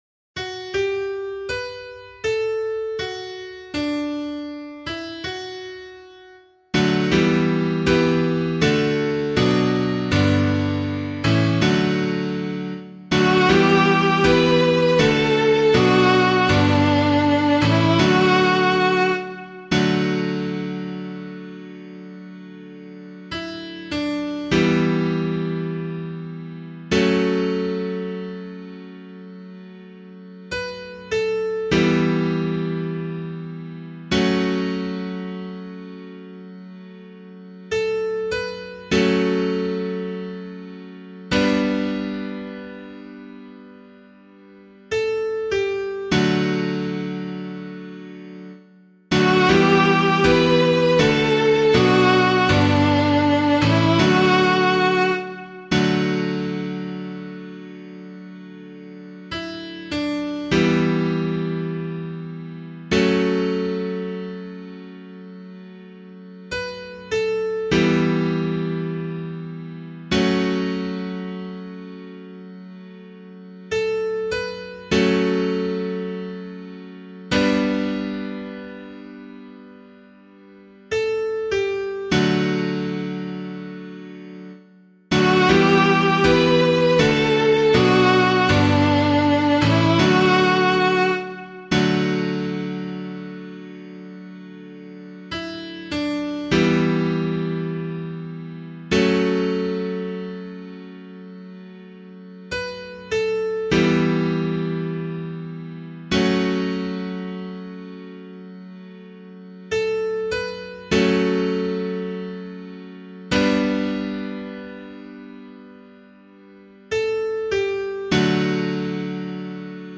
015 Lent 3 Psalm C [LiturgyShare 6 - Oz] - piano.mp3